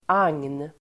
Ladda ner uttalet
Uttal: [ang:n]